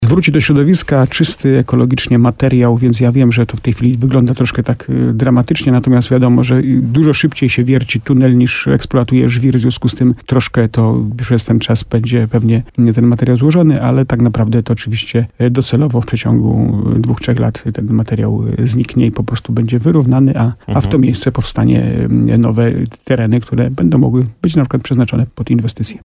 Docelowo zniknie w przeciągu 2-3 lat, a miejsce to będzie wyrównane i powstaną nowe tereny, które będą mogły być przeznaczone na przykład pod nowe inwestycje – mówił w programie Słowo za Słowo w radiu RDN Nowy Sącz burmistrz Starego Sącza Jacek Lelek.